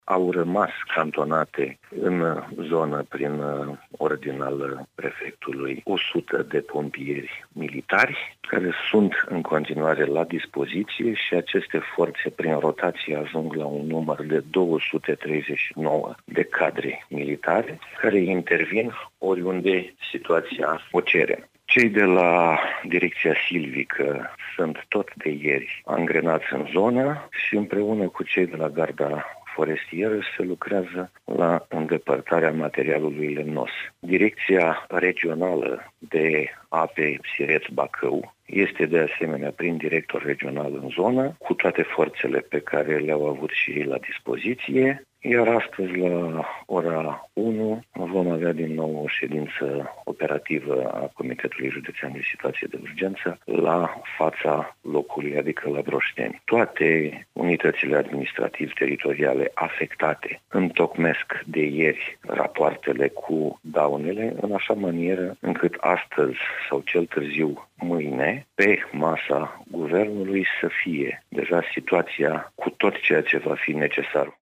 Toți cei care locuiesc în proximitatea pârâului Neagra, ce străbate oraşul Broşteni și care a ieșit din matcă în urma precipitațiilor abundente, au fost evacuați, a declarat prefectul de Suceava, Traian Andronachi.